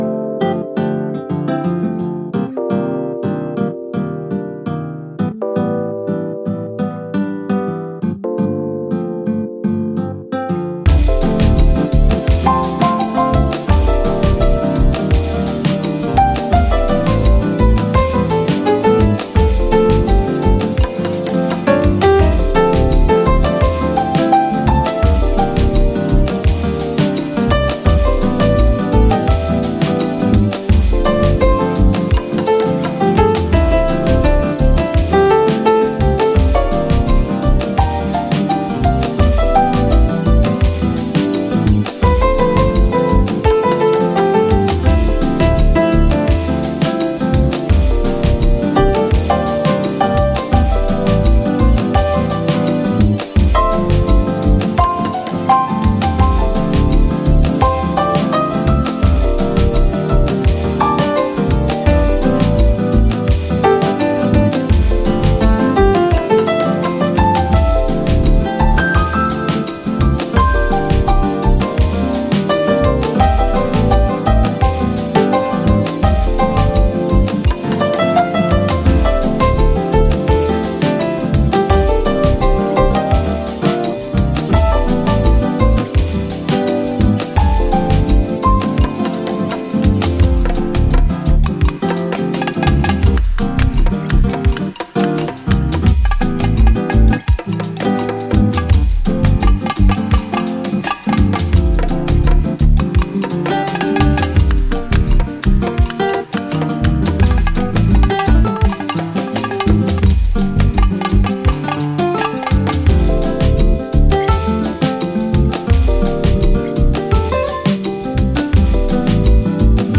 a powerful chill-out unit
keyboardist